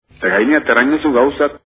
Navarro-labortano
2/ No se da la palatalización tras una vocal i: edozoini, gainetik, etxezaina, etxeraino...